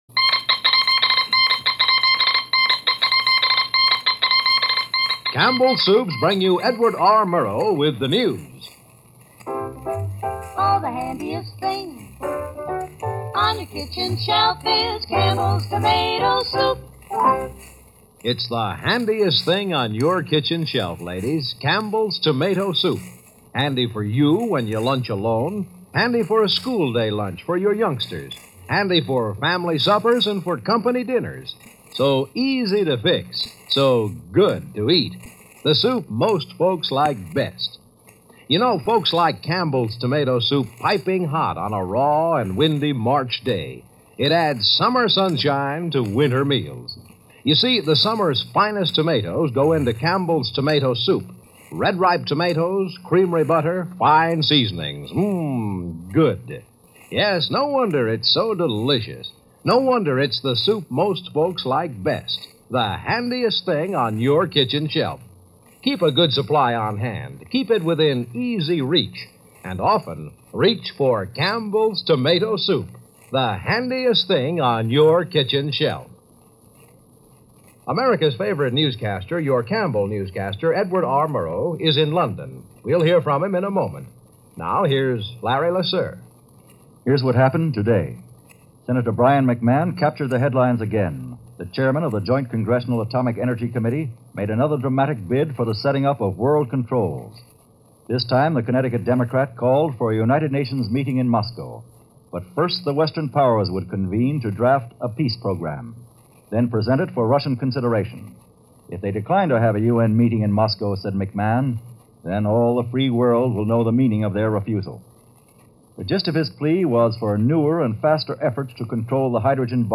News on the Cold War, Atomic Energy , the UN and Capitol Hill.
CBS-Edward-R.-Murrow-March-.mp3